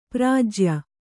♪ pfrājya